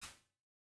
UVR_resources / impulse /VS8F-2 /232-R2_SoftAmb.wav
232-R2_SoftAmb.wav